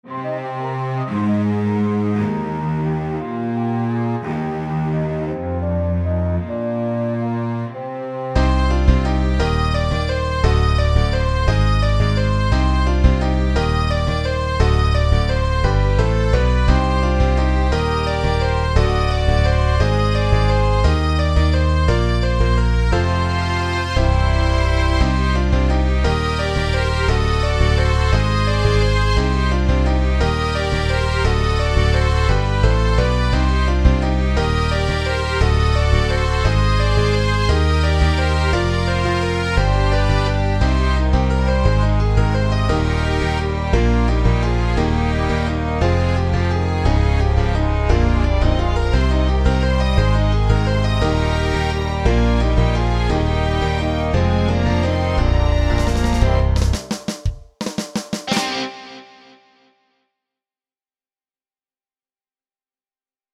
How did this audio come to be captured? I still play it on the piano now and then and we did it using Garageband in 2007.